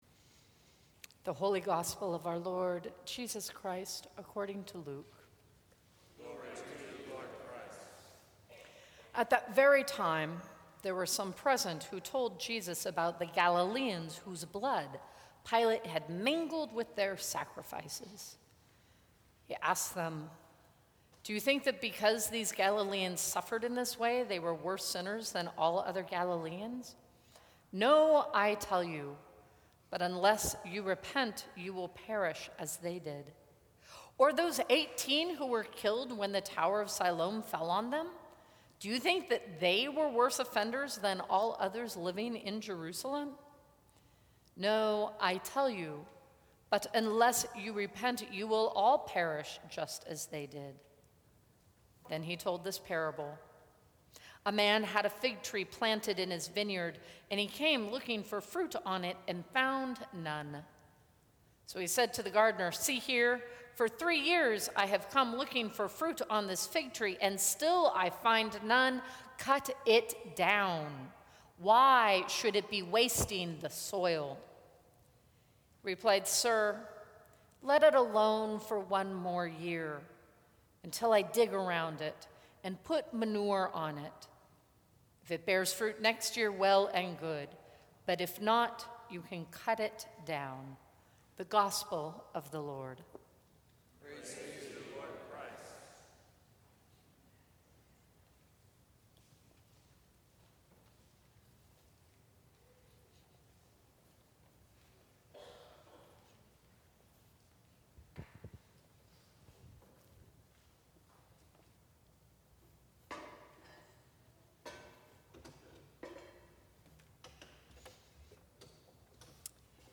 Sermons from St. Cross Episcopal Church Third Sunday in Lent Feb 29 2016 | 00:16:11 1x 00:00 / 00:16:11 Subscribe Share Apple Podcasts Spotify Overcast RSS Feed Share Link Embed